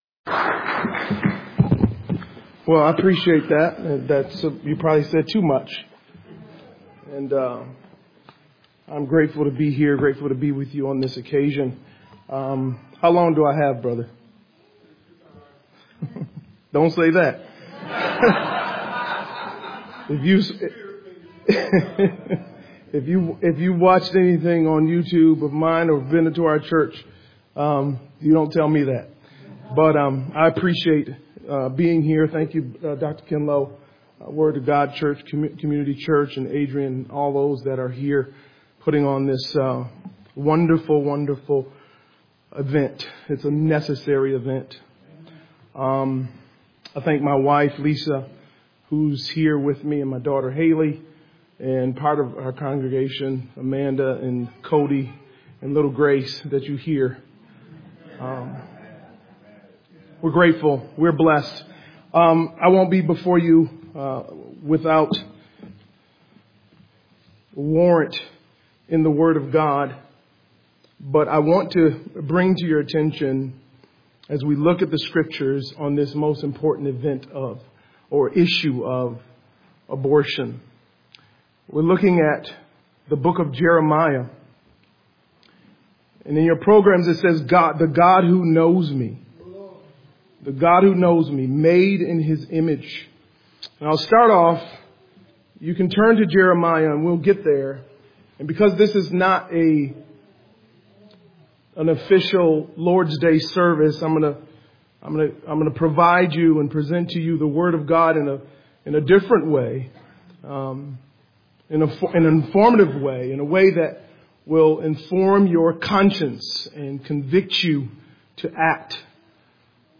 Pro-Life Sermon (Audio)